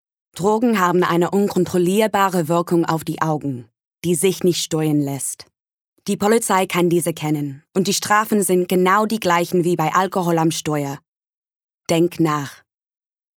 Young, Fresh with Warm Husky Tones
Commercial, Confident, Strong, Serious